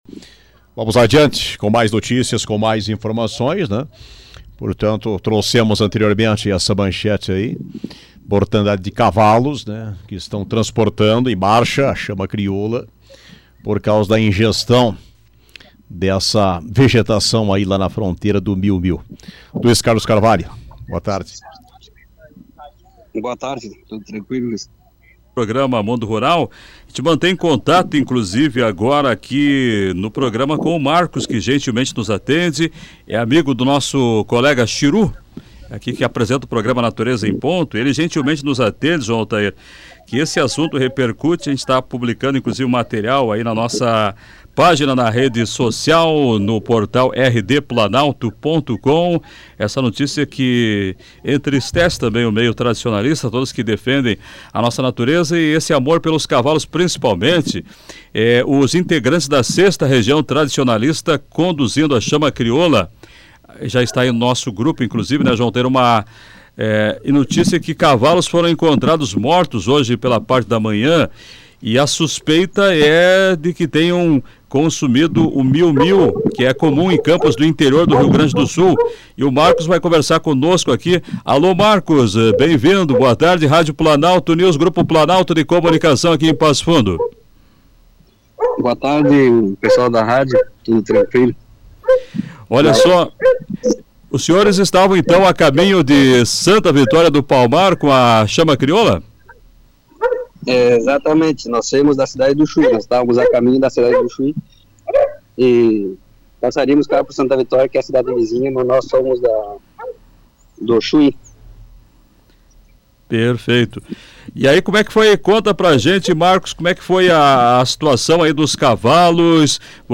ENTREVISTA-CAVALO-1.mp3